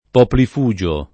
vai all'elenco alfabetico delle voci ingrandisci il carattere 100% rimpicciolisci il carattere stampa invia tramite posta elettronica codividi su Facebook poplifugio [ poplif 2J o ] s. m. (stor.) — antica festa romana